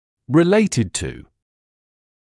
[rɪ’leɪtɪd tuː][ри’лэйтид туː]связанный с; имеющий отношение к